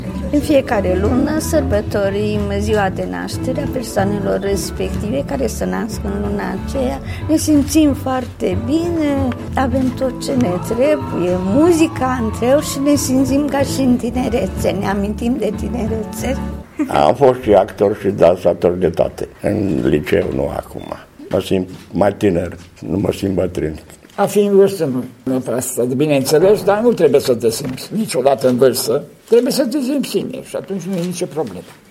Vârstnicii spun că nu le este greu, și sunt chiar ocupați de evenimentele organizate la cămin: